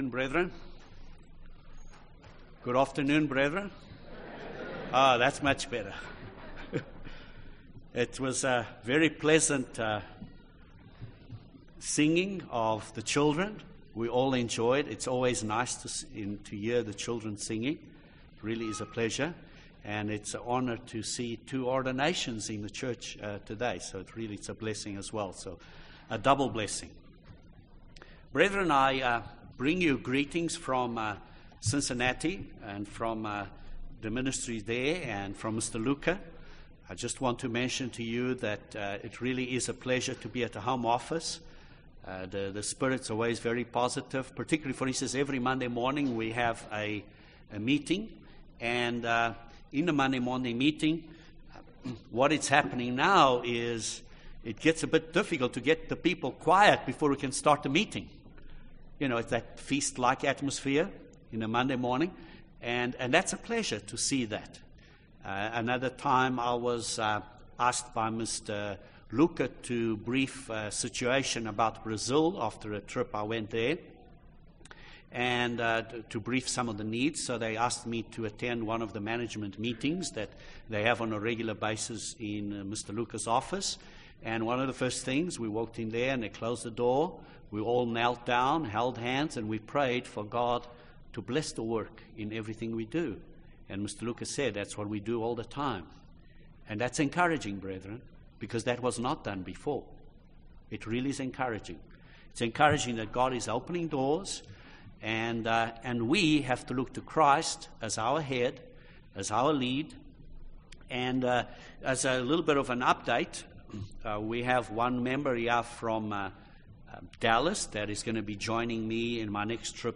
How will the world change? This sermon discusses key events leading to Jesus Christ establishing the Kingdom of God on earth and how that paradise will be created on Earth.